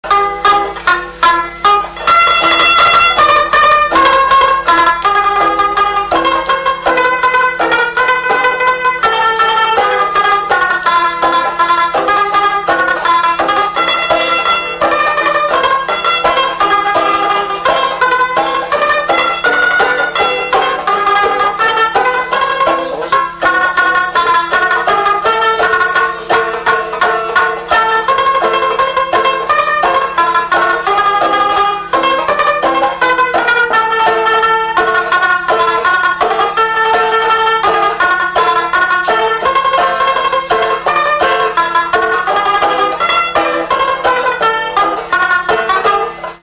Instrumental Fonction d'après l'analyste gestuel : à marcher ; Usage d'après l'analyste circonstance : fiançaille, noce
Pièce musicale inédite